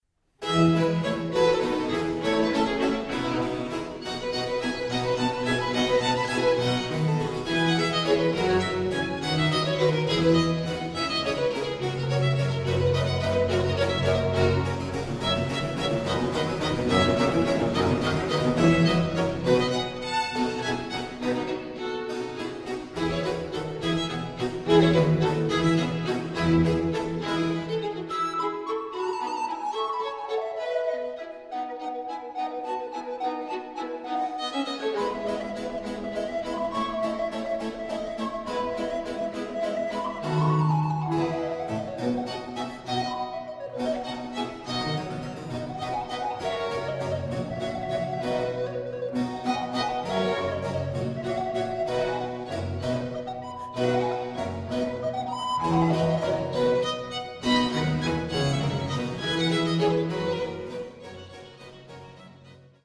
Satz Vivace (rechte Maustaste)